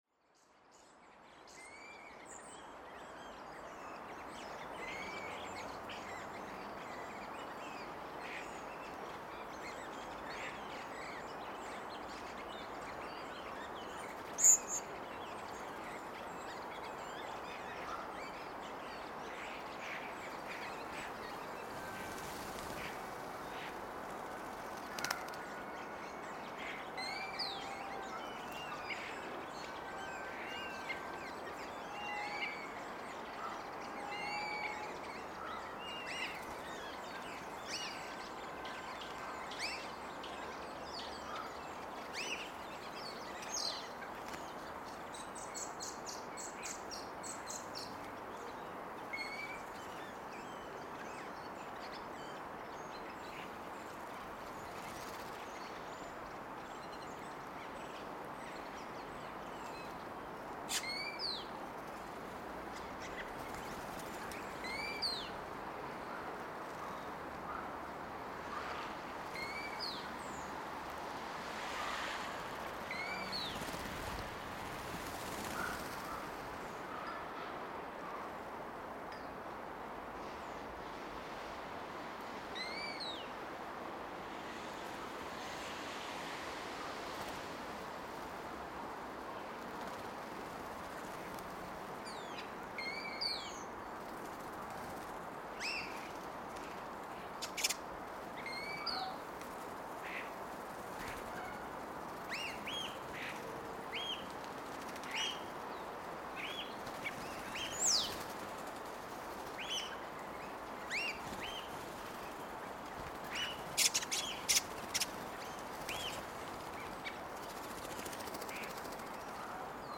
Stillti ég upp hljóðnemum í um tveggja metra fjarlægð frá fóðurstað.
En þennan dag féll talsverður snjór í borginni sem deifði mjög mikið hávaðann frá umferðinni. Það heyrist því mun betur í vænjaþyti og tísti fuglanna. Þarna voru fuglar eins og starri, skógarþröstur og svartþröstur. Í fjarlægð má heyra í krumma og hundi.
______________________________________ Feeding Starlings, Redwings, and Blackbirds.
The birds was spooked around the Blimp windshield (it looks like big fat gray cat) so they fly up and down frequently during the recording session.